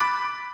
piano_D5_B4_2.ogg